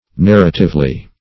narratively - definition of narratively - synonyms, pronunciation, spelling from Free Dictionary Search Result for " narratively" : The Collaborative International Dictionary of English v.0.48: Narratively \Nar"ra*tive*ly\, adv.
narratively.mp3